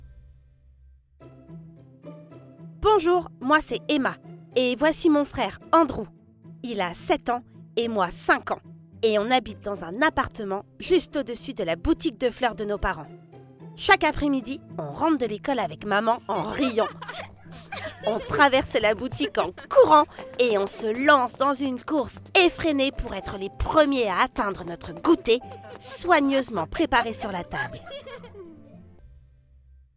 Une histoire sur les relations entre frères et sœurs, lu par plusieurs comédiens, illustré de musiques et ambiances sonores pour les plus petits !